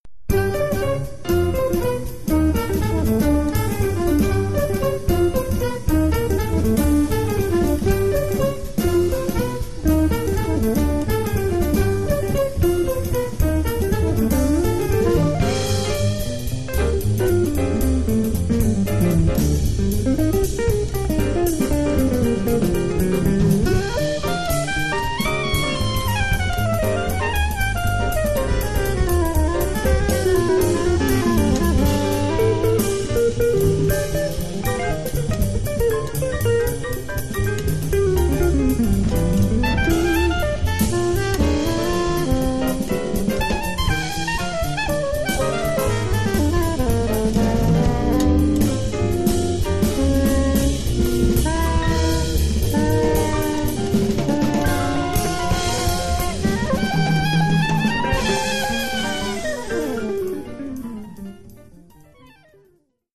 alcune forme post-hardbop